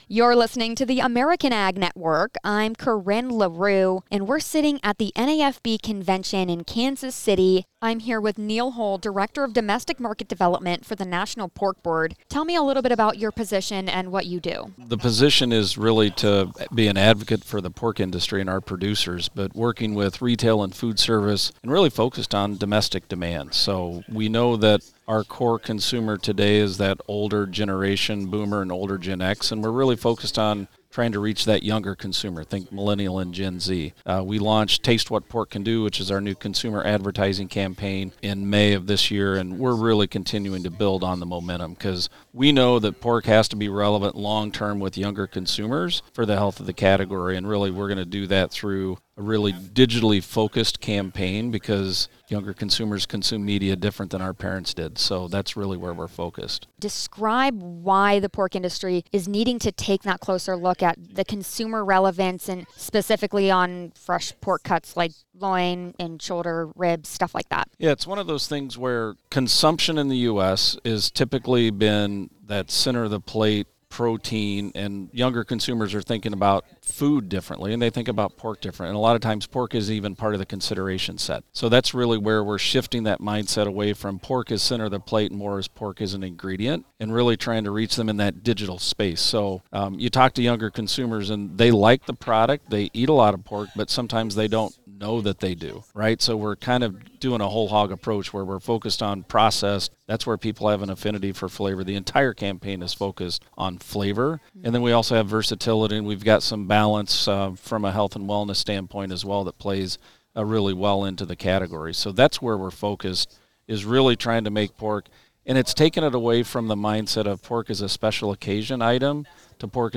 During the 2025 NAFB Convention in Kansas City, MO